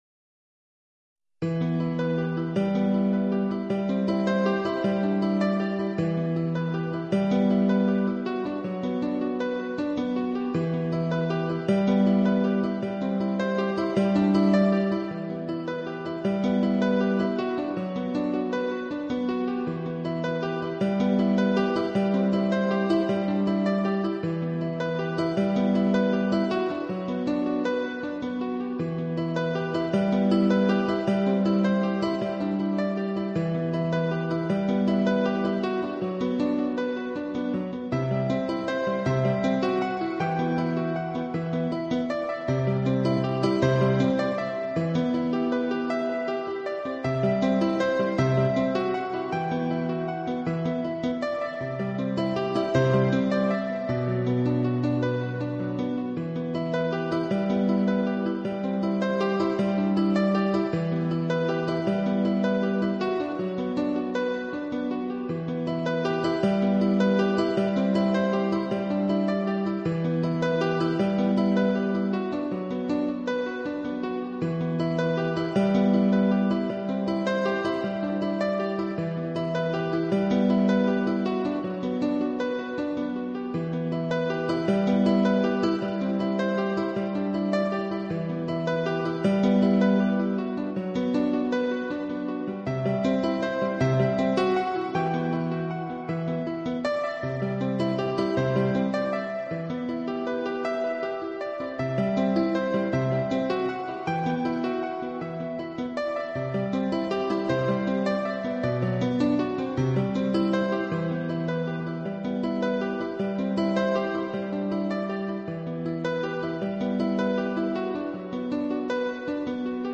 版本：钢琴